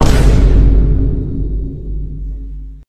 menu-play-click.mp3